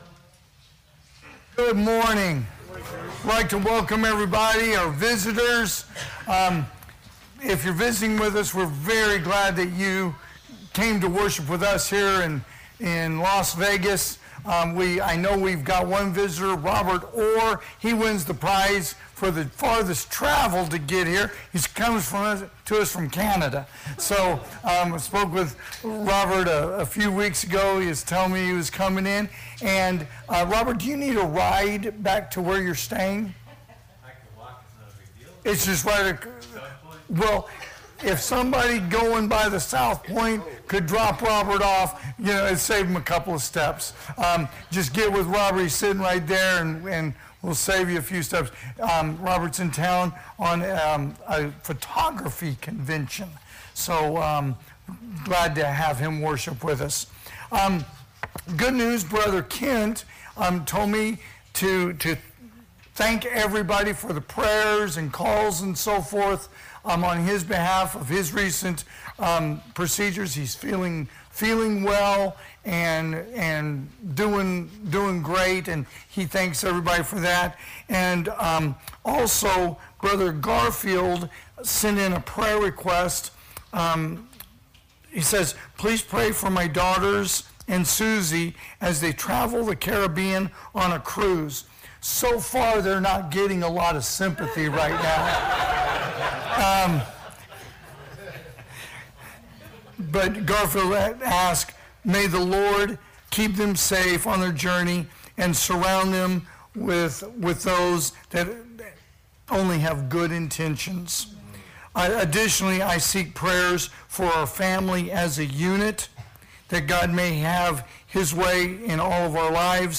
AM Worship